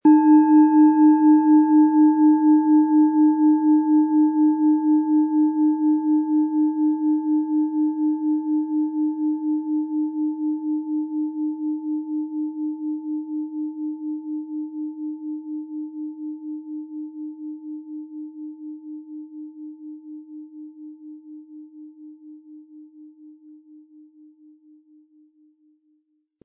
Tibetische Herz-Fuss-Bauch- und Kopf-Klangschale, Ø 19,3 cm, 800-900 Gramm, mit Klöppel
HerstellungIn Handarbeit getrieben
MaterialBronze